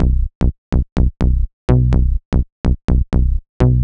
cch_bass_loop_glamour_125_B.wav